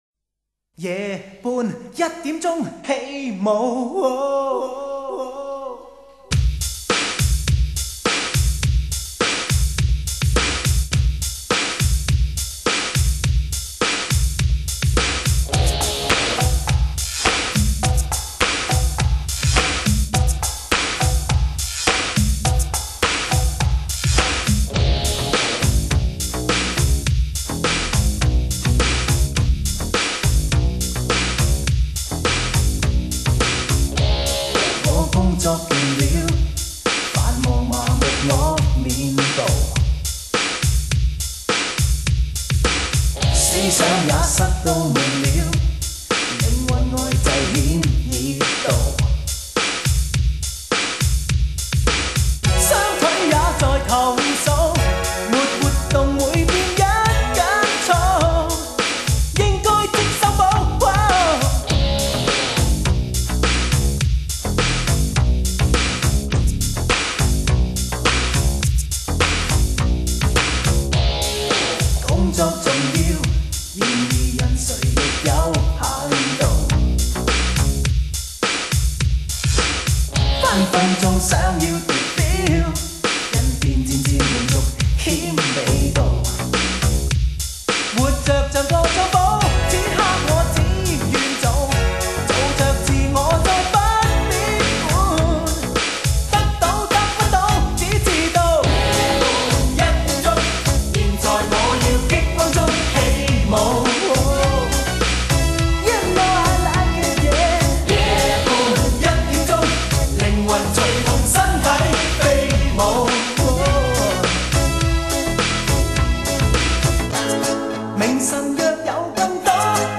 风格: 流行